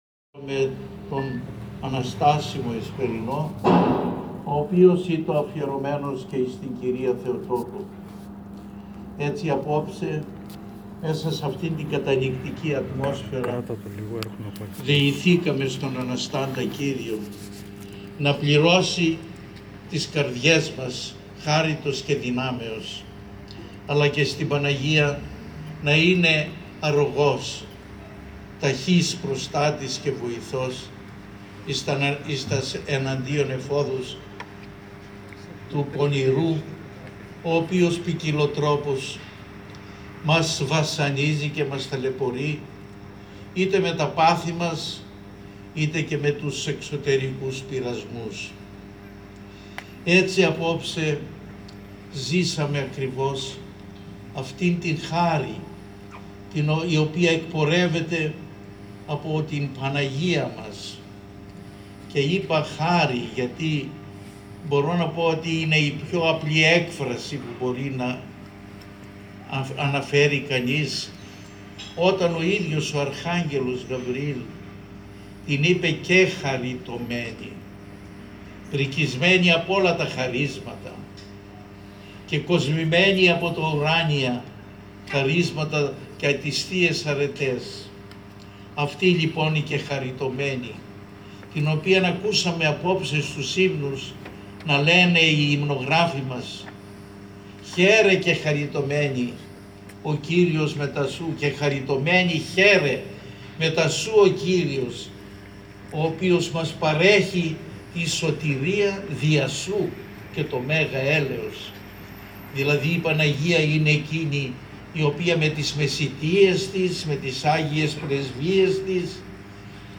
Ὁμιλία
εἰς τόν Πανηγυρικόν Ἐσπερινόν